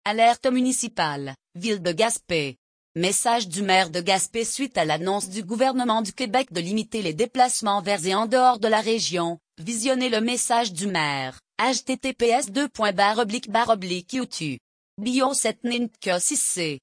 Message du maire de Gaspé